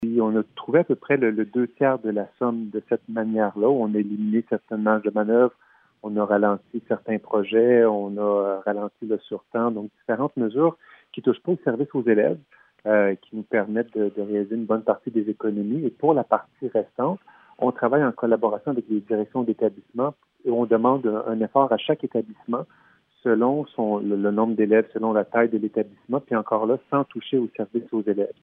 Nouvelles